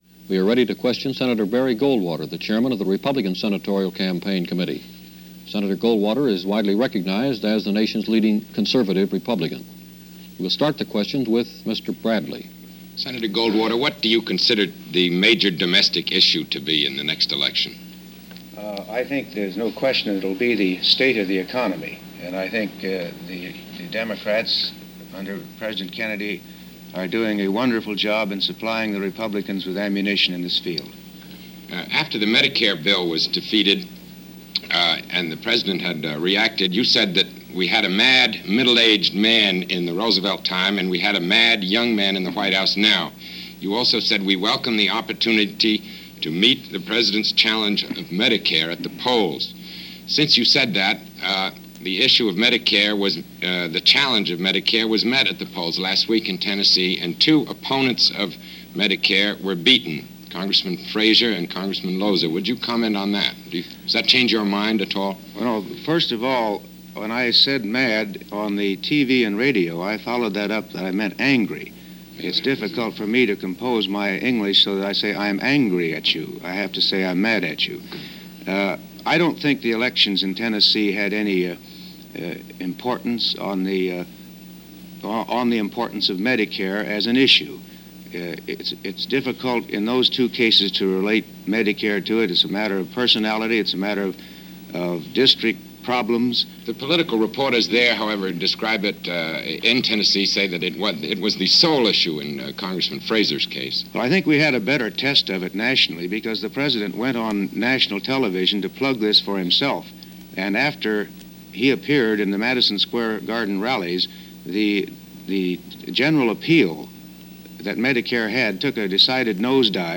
He is asked about what he felt the domestic issues in the upcoming mid-term elections were. First, the state of the economy, and second was the issue of Medicare which the Senate had rejected, but which the general population were in favor of. Goldwater is asked if the recent defeats of two Congressional opponents of Medicare were beaten in a mid-term election in Tennessee.